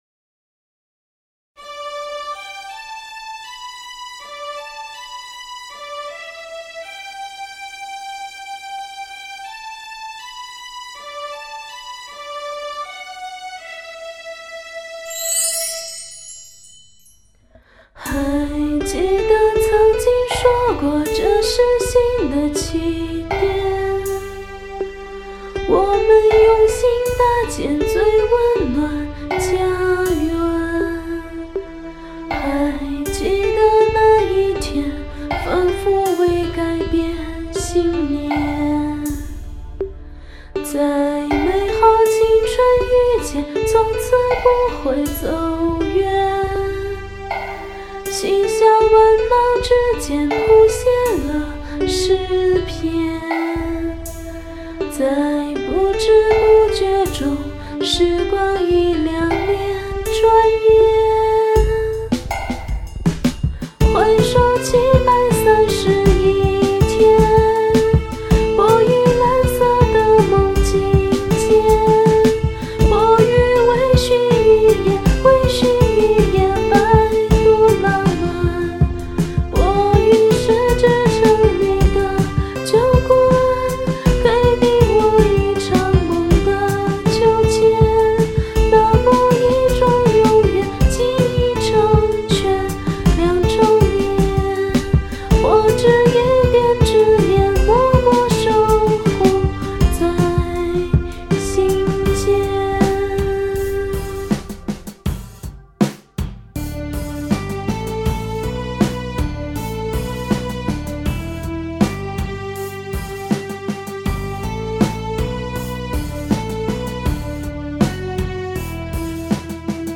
曲风：民谣